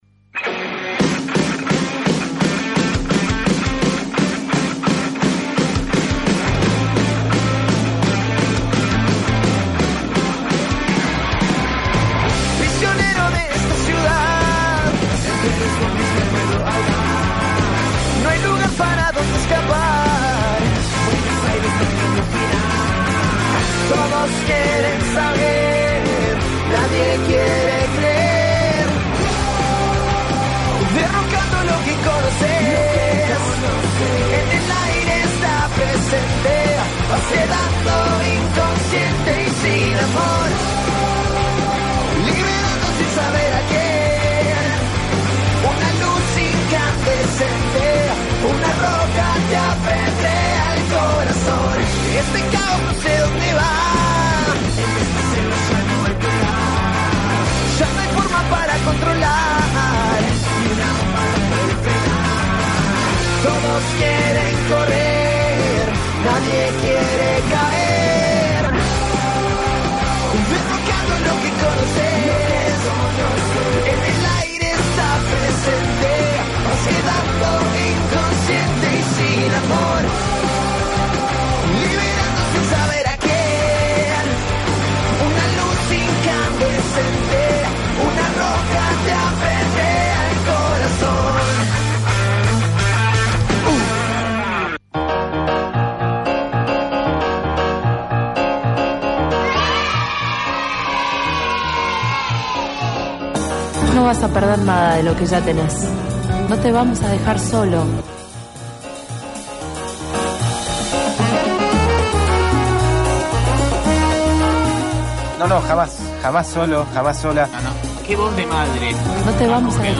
Por eso fue que tuvimos un comienzo bien arriba escuchando a las tres bandas que nos acompañaran en esta movida autogestiva.